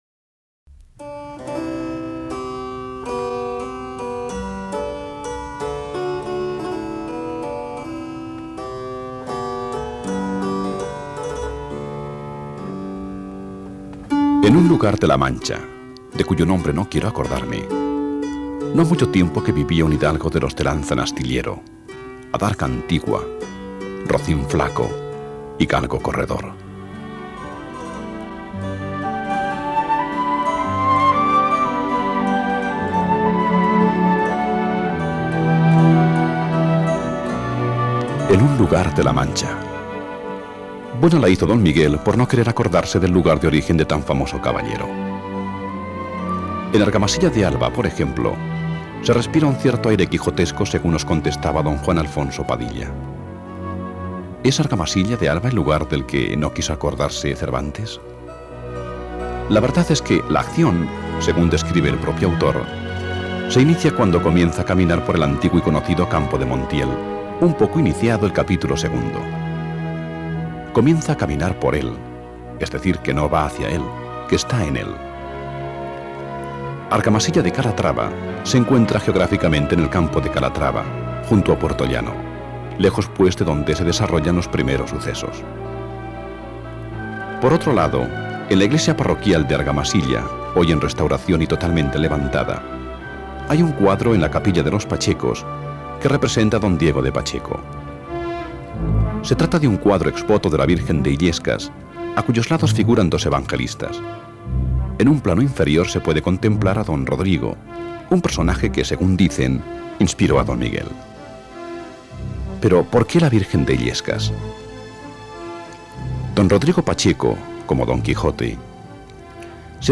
Programa radiofónico número 1 de la serie «En un lugar de La Mancha»